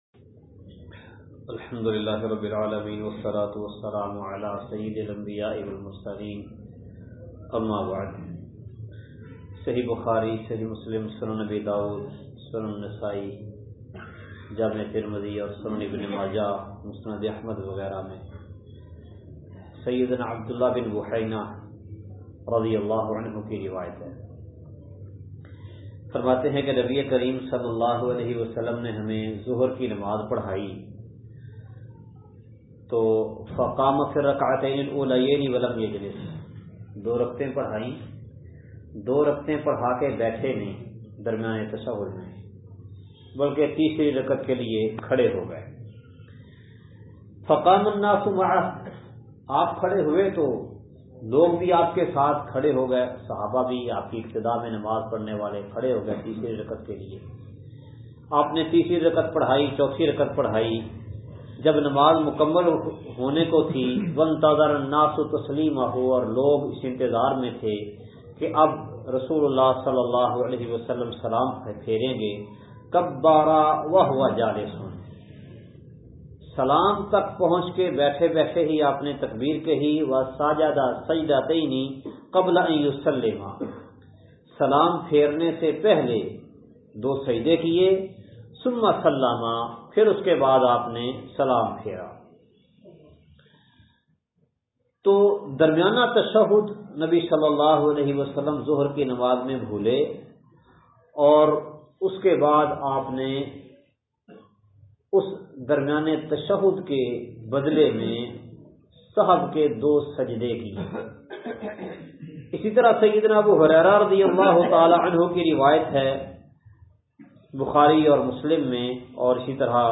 سجدہ سہو کا سبب درس کا خلاصہ سجدہ سہو دو مواقع پر ہے۔